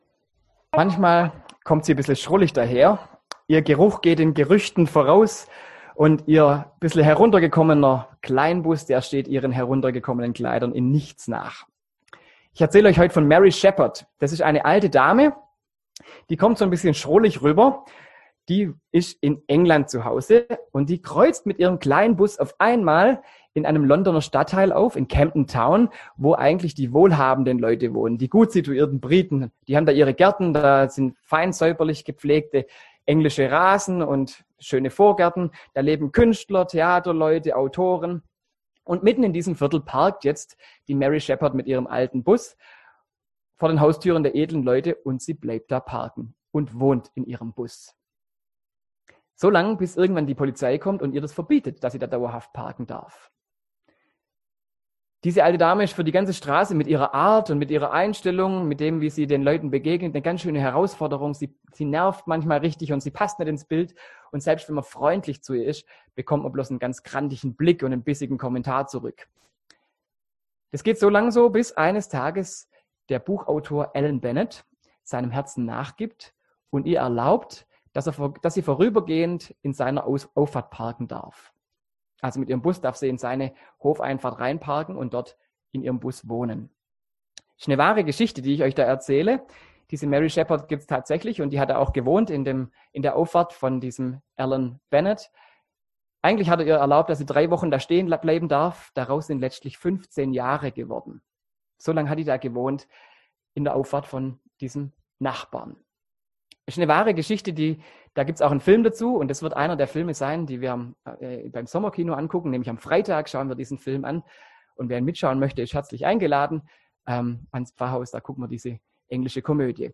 Predigt
im Onlinegottesdienst am 7. Sonntag nach Trinitatis